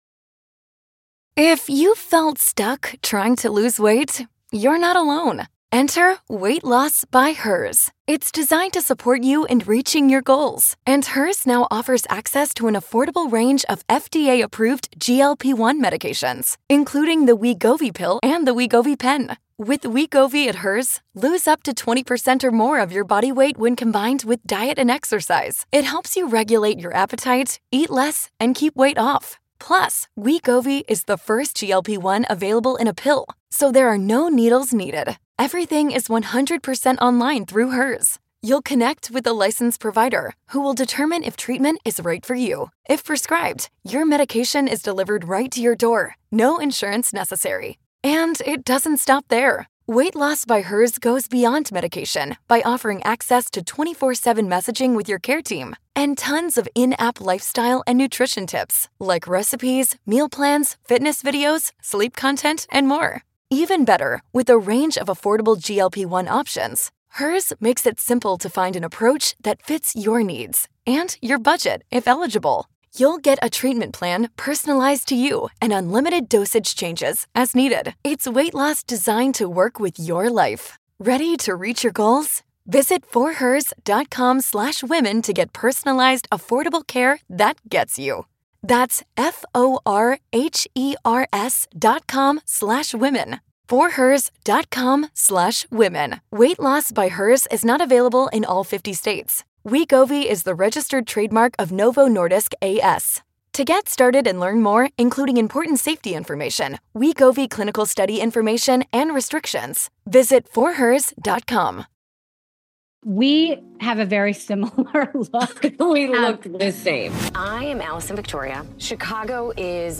Whether you’re a fan of jaw-dropping transformations, a budding entrepreneur, or just someone who loves a good story about resilience and reinvention, this conversation will inspire you to dream bigger and build fearlessly.